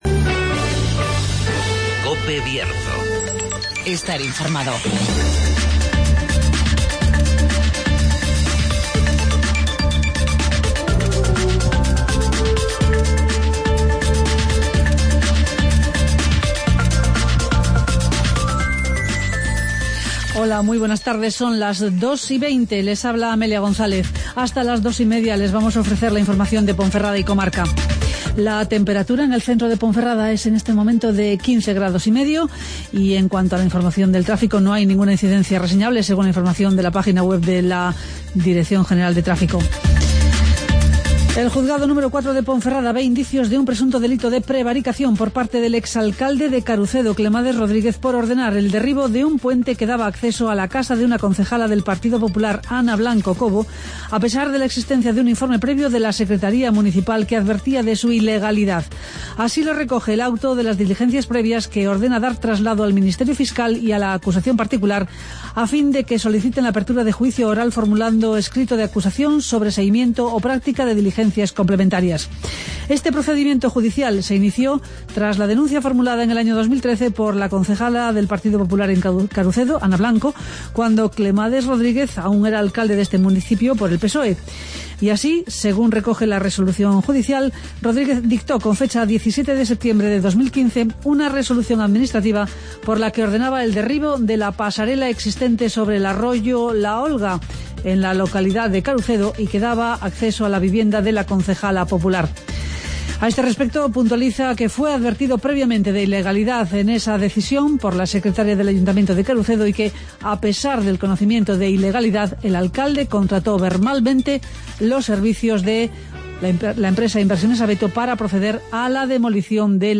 Escucha las noticias de Ponferrada y comarca en el Informativo Mediodía de COPE Bierzo